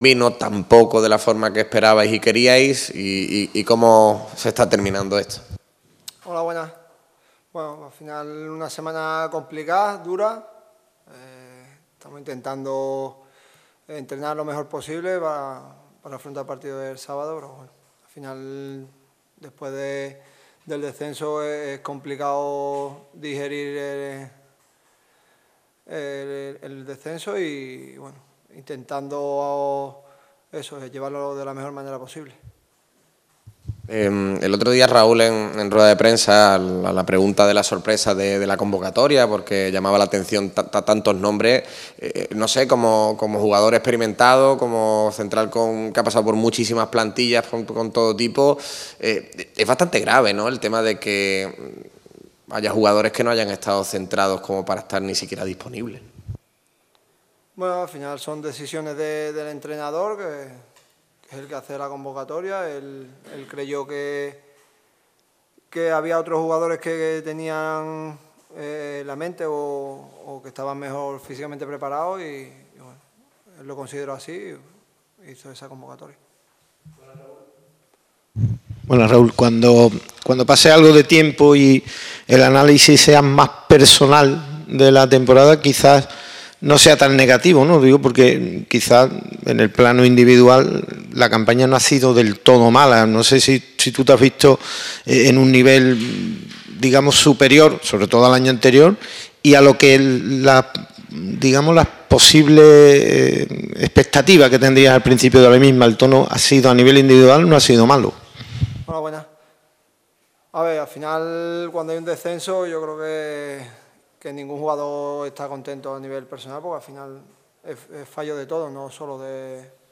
Oye la rueda de prensa completa en este enlace: